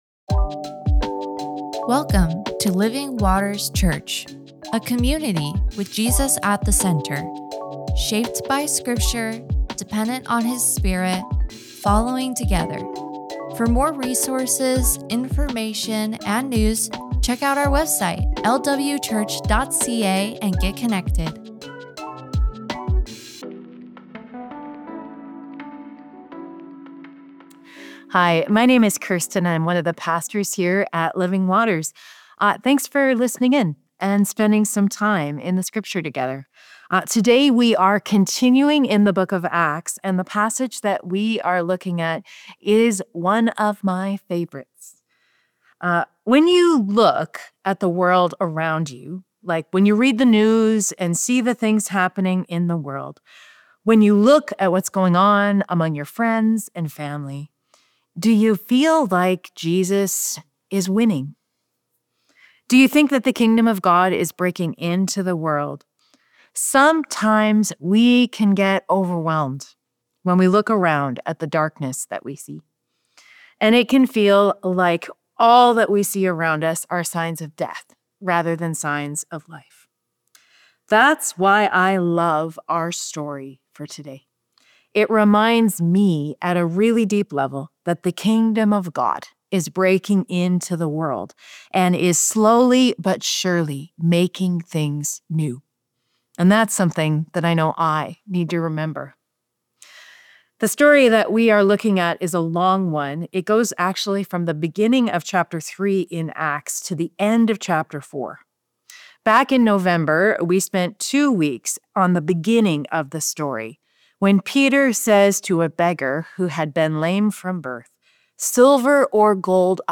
Sermons | Living Waters Church